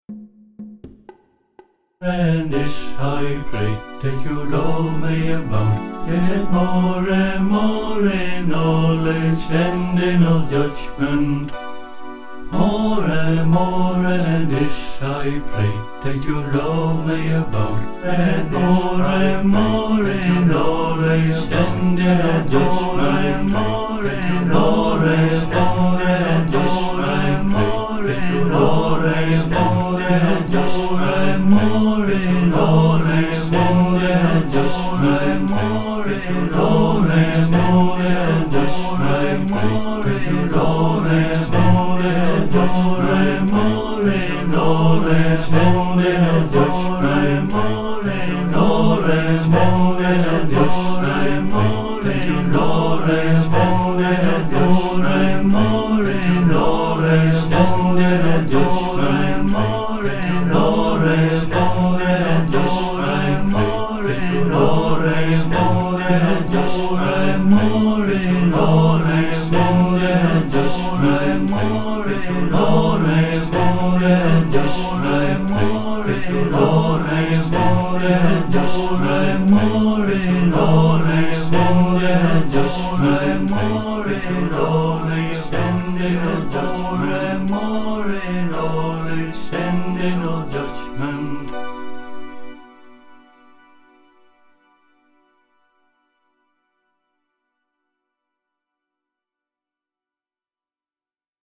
(a three part round)